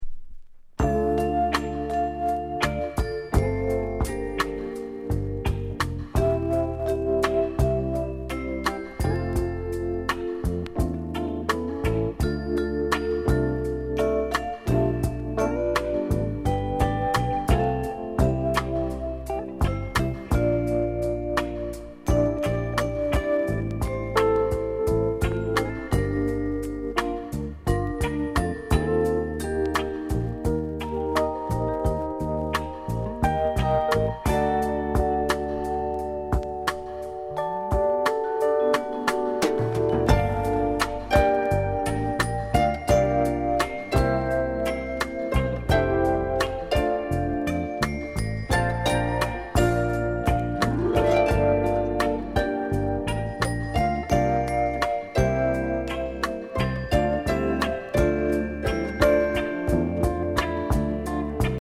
INST COVER